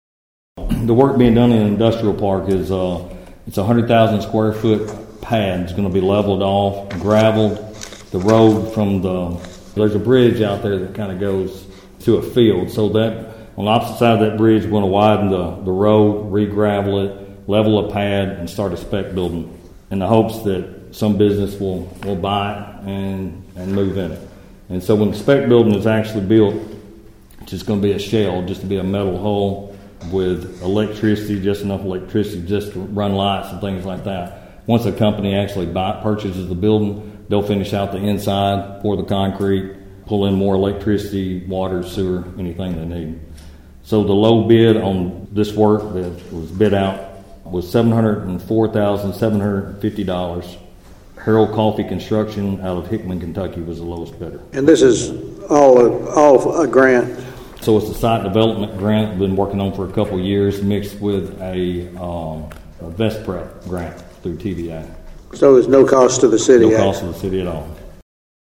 City Manager Johnny McTurner explained the scope of work and the lowest bid of nine submitted.(AUDIO)